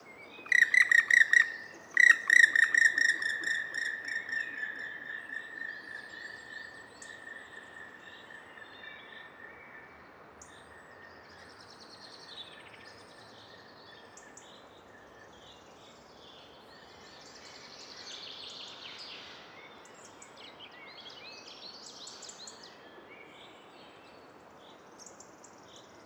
Schwarzspecht Ruf
Der-Schwarzspecht-Ruf-Voegel-in-Europa.wav